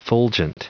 Prononciation du mot fulgent en anglais (fichier audio)
Prononciation du mot : fulgent